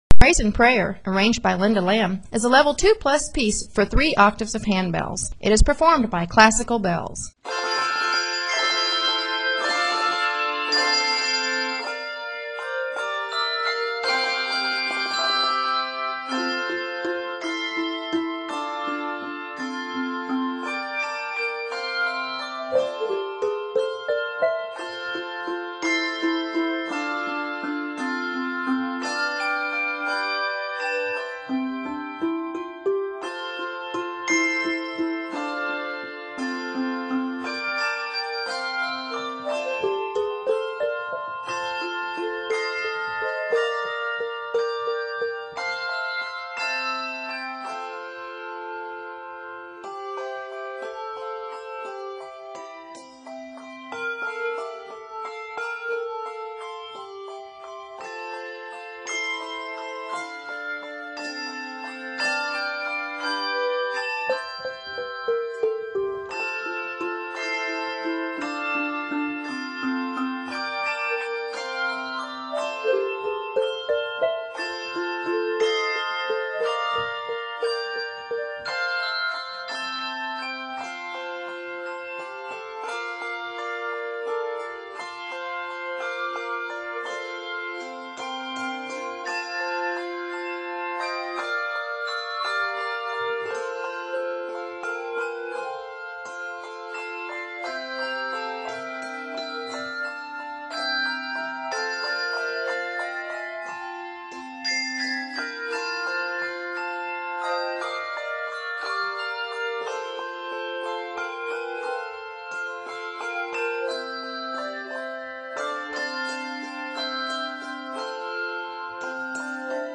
hymn tunes
Lots of special techniques add interest.